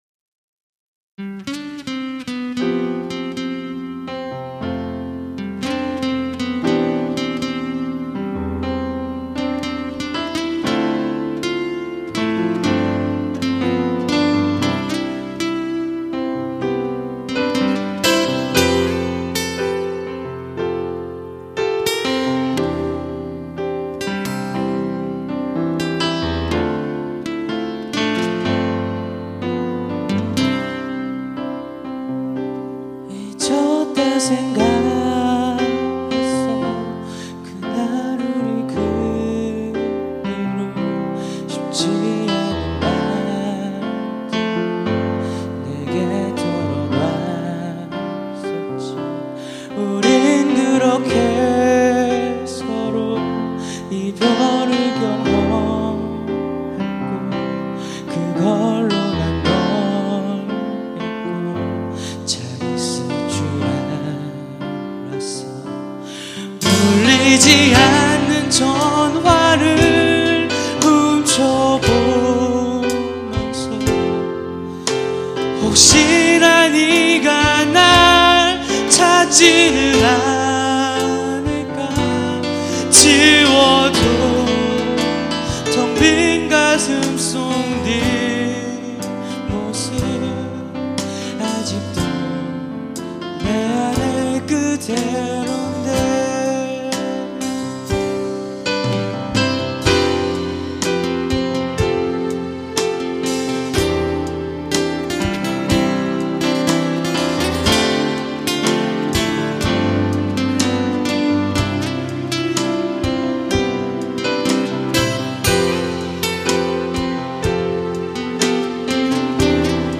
2004년 제 24회 정기 대공연
홍익대학교 신축강당
노래
어쿠스틱기타
드럼
신디사이저